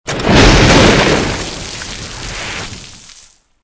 audio: Converted sound effects
AA_throw_stormcloud.ogg